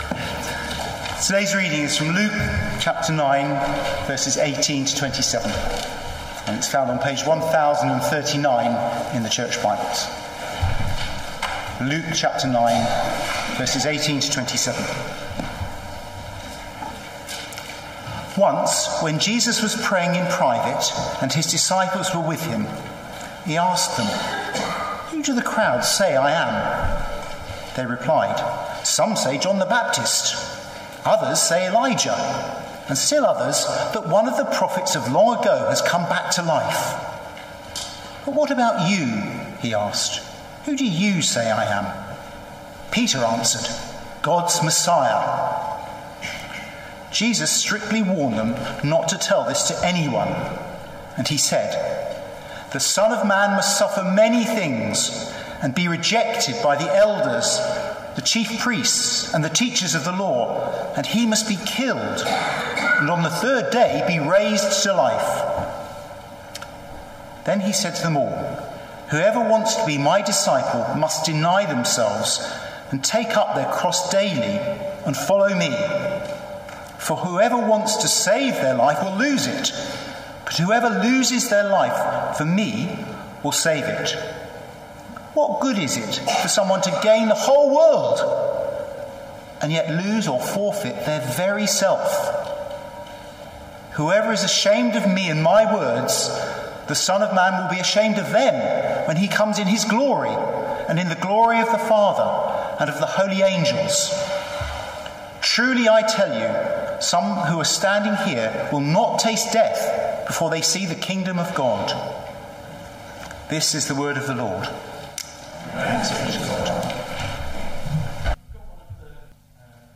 Baptism service – Luke 9:18-27
Luke 9:18-27 Service Type: Sunday Morning Sermon Notes- Luke 9:18-27 Who is Jesus?